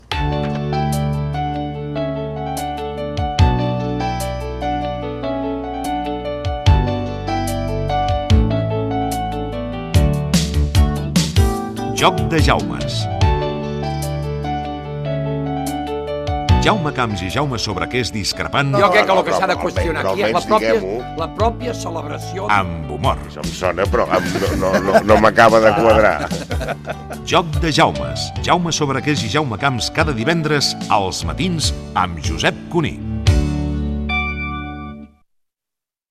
Promoció de la secció "Joc de Jaumes"
Data emissió 1997-09-30 Banda FM Localitat Barcelona Comarca Barcelonès Durada enregistrament 00:36 Idioma Català Notes Fragment extret de l'arxiu sonor de COM Ràdio.